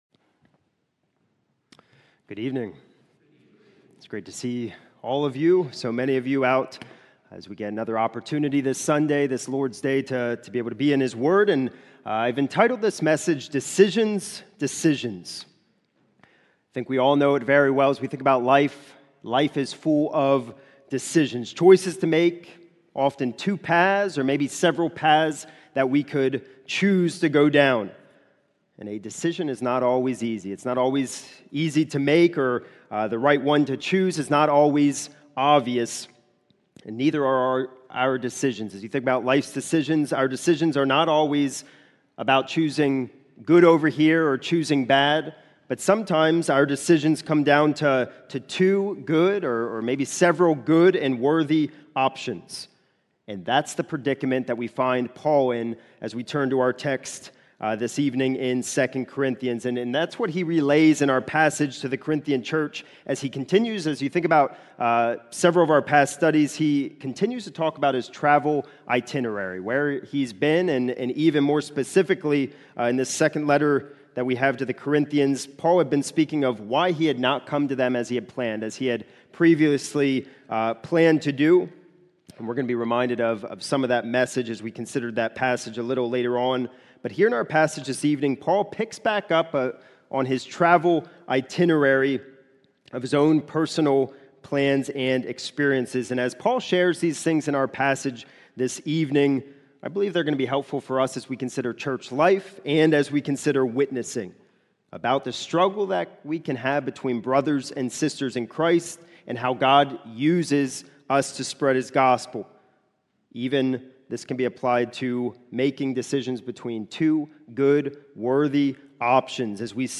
Decisions, Decisions – 2 Corinthians 2:12-17 (PM) | Lebanon Bible Fellowship Church